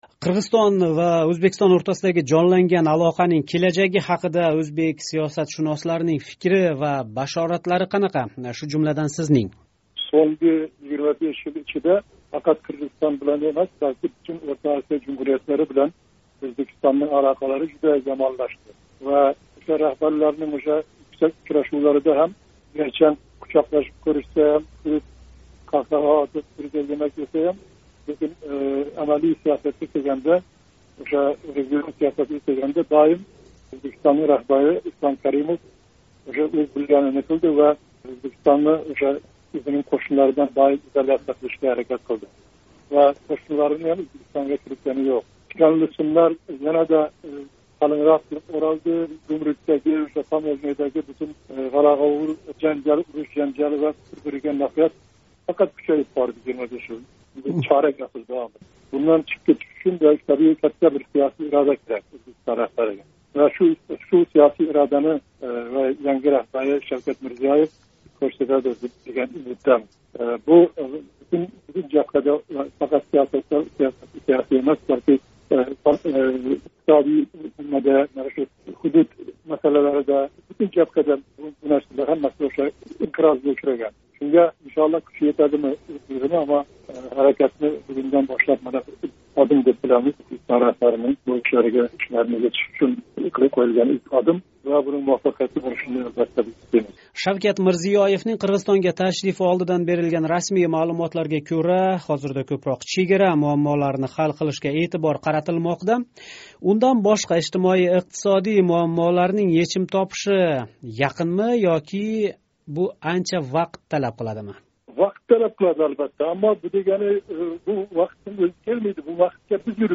“Эрк” демократик партиясининг лидери Муҳаммад Солиҳни Ўзбекистон президентининг Қирғизистонга давлат ташрифи муносабати билан суҳбатга тортдик. “Озодлик” радиосининг қирғиз хизмати саволларига жавоб бераркан, М.Солиҳ Қирғизистон президенти сайлови Бишкек ва Тошкент муносабатларига қандай таъсир қилиши мумкинлиги ҳақида ҳам фикр билдирди.